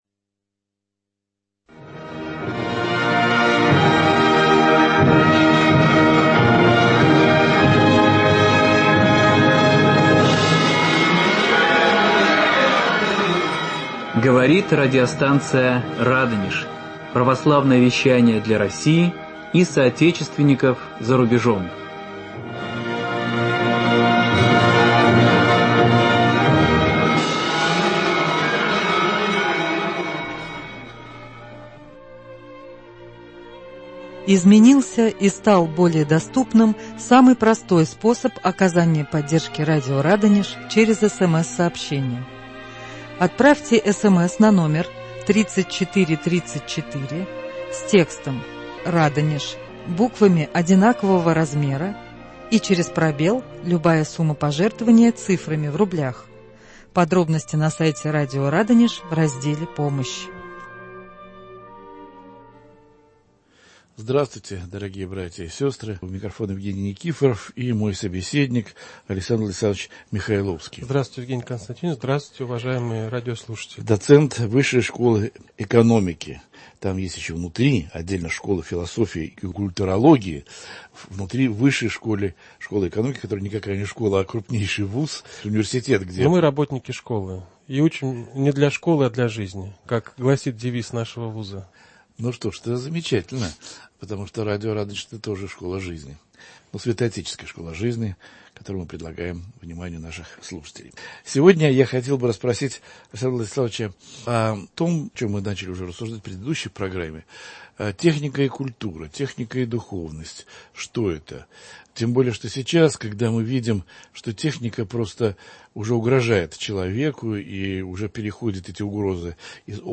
В студии радио